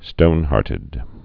(stōnhärtĭd)